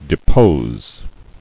[de·pose]